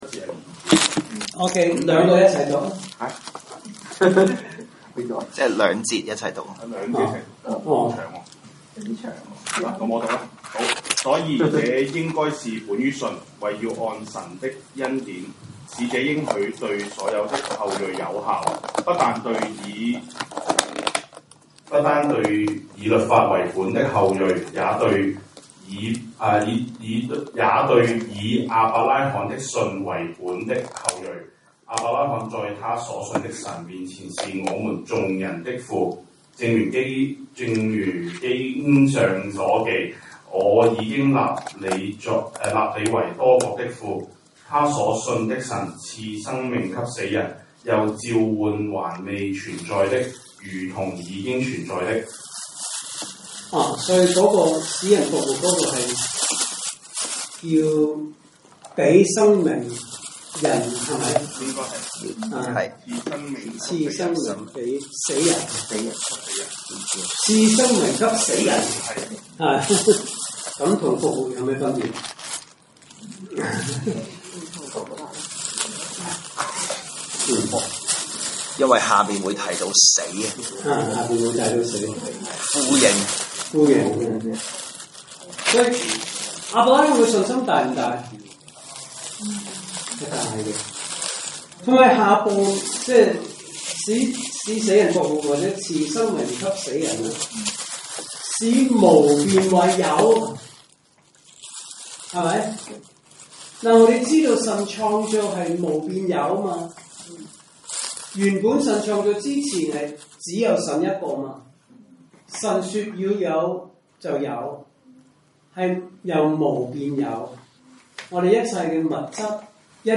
證道信息
來自講道系列 "查經班：羅馬書"